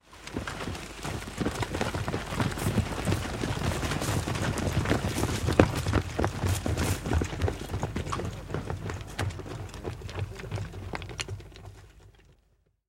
Шум шагов солдат